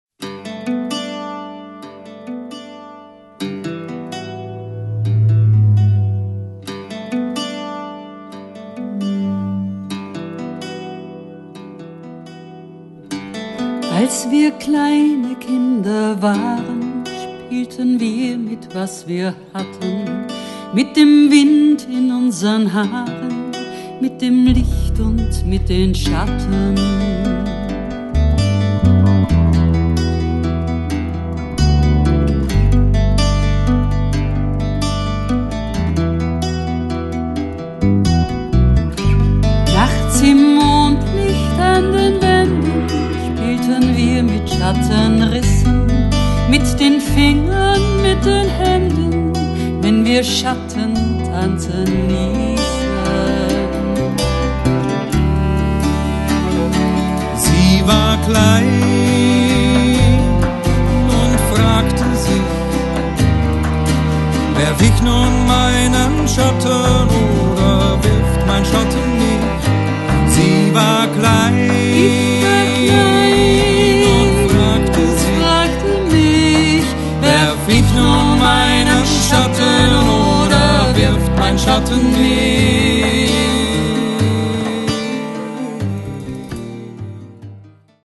Aufgenommen und gemischt zwischen März und Mai 2007